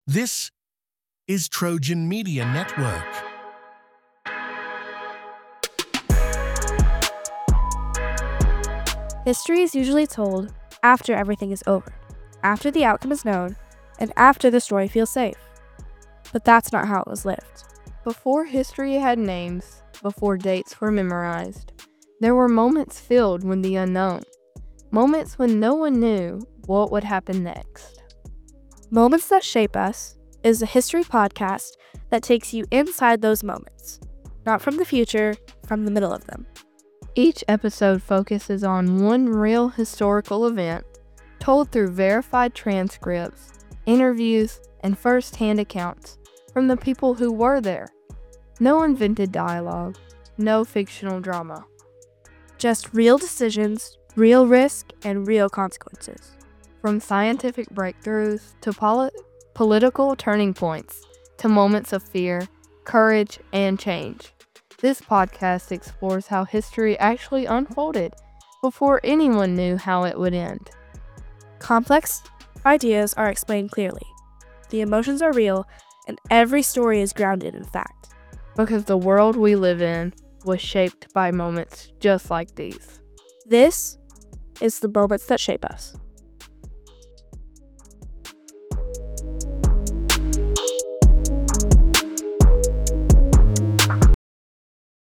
Trailer
Moments That Shape Us is a serious, documentary-style history podcast that takes listeners inside the moments when history was still unfolding.